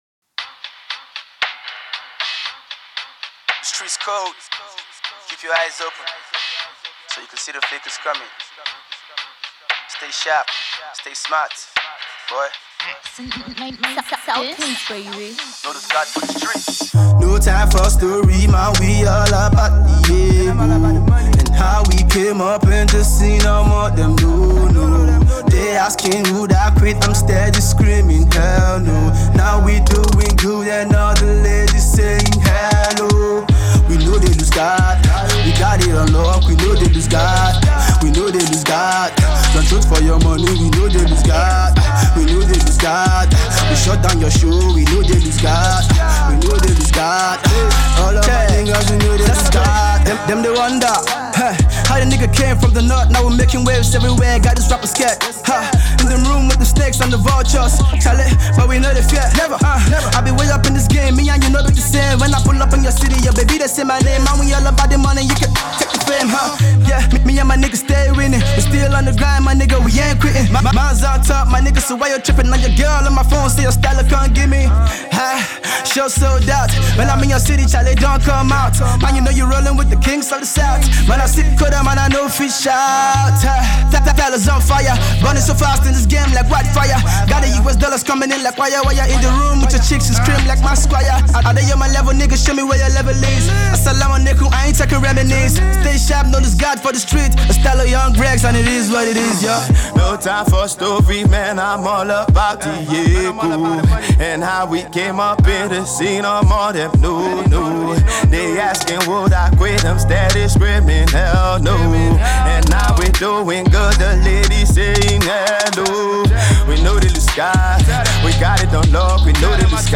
This is a sure new banger